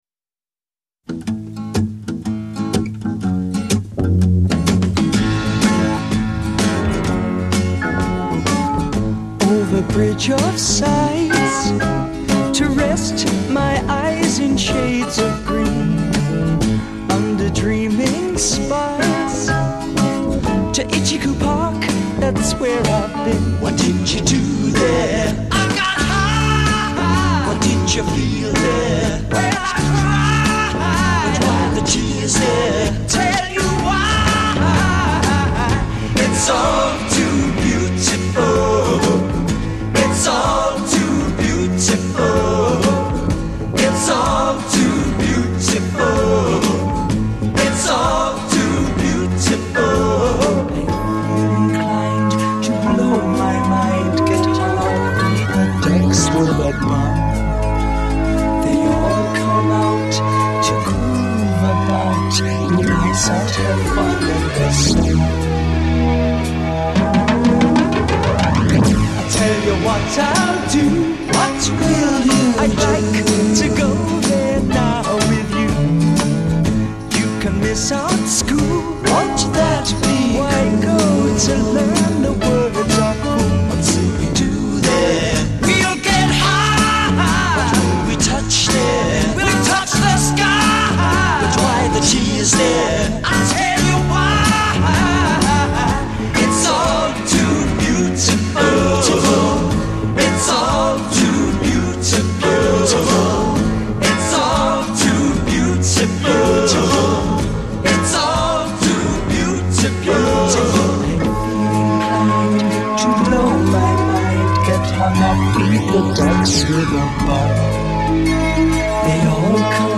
Recorded at Olympic Studios, London.
Intro 0:00 8 guitar, add bass, drums, and organ
part 2 : 12 Double-tracked lead vocal. f
refrain part 1   16+ Repeat and fade. c